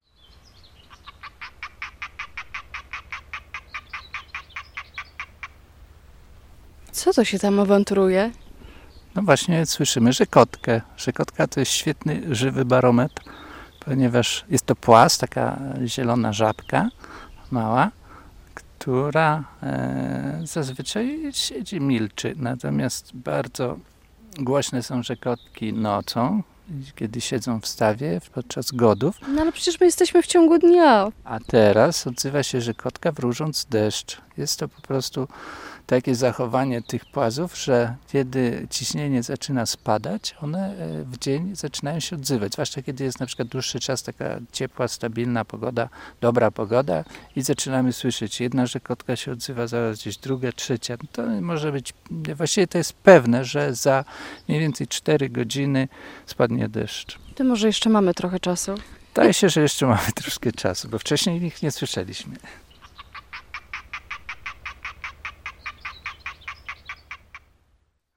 1707c_-_NOD_-_Puszcza_Borecka_-_rzekotka_drzewna.mp3